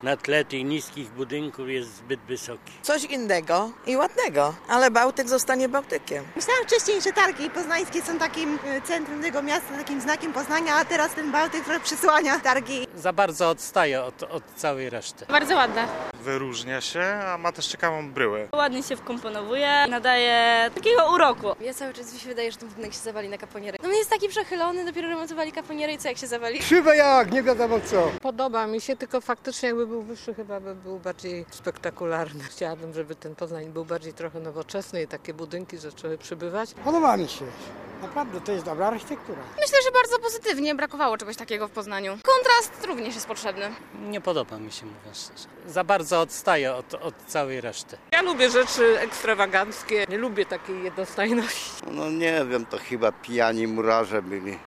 4jb3xa96sb7ufxf_sonda-baltyk.mp3